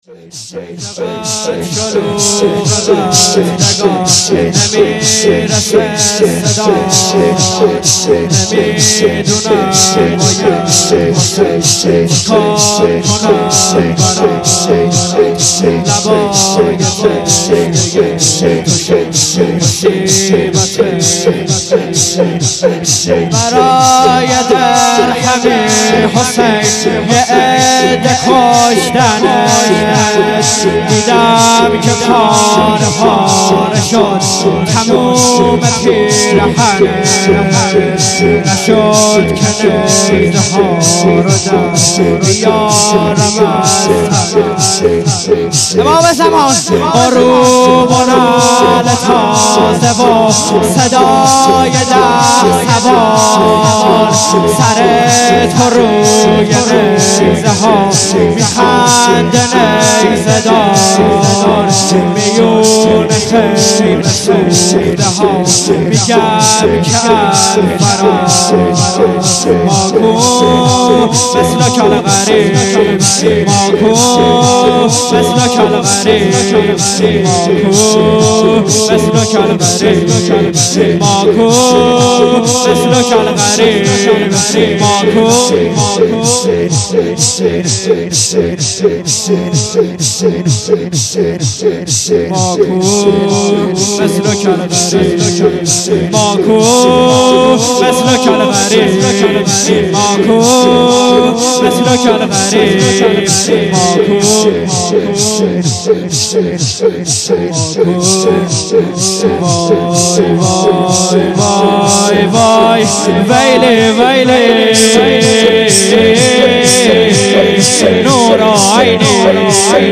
شور 1 شب هفتم محرم 98